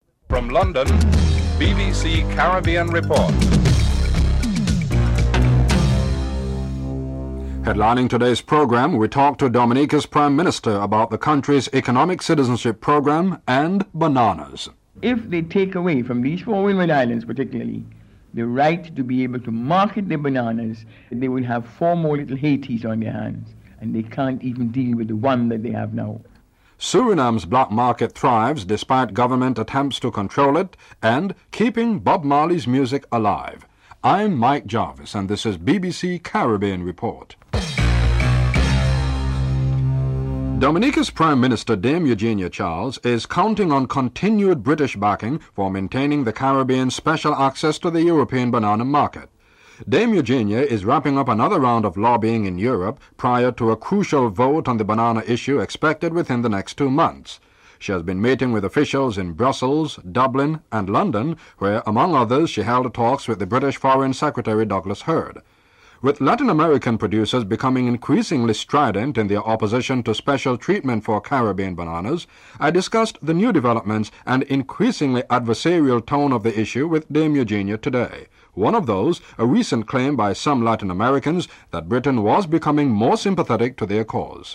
The British Broadcasting Corporation
1. Headlines (00:00-00:39)